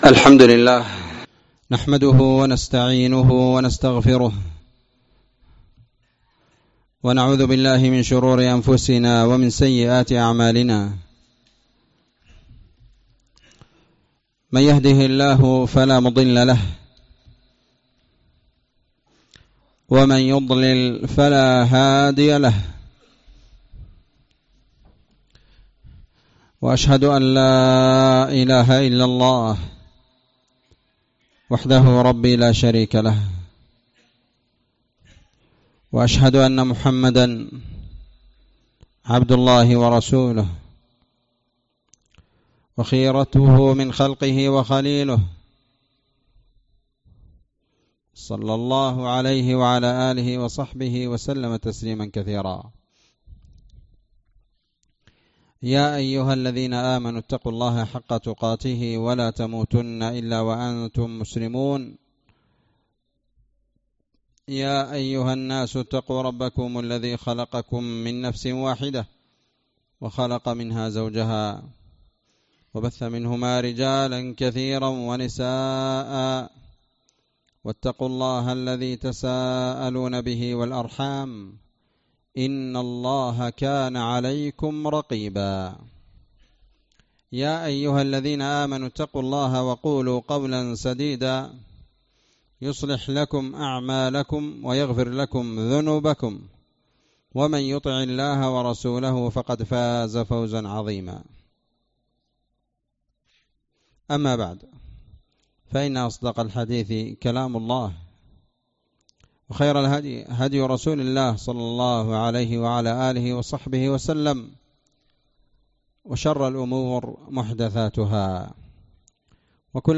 محاضرة قيمة